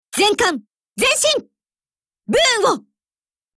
Cv-30309_warcry.mp3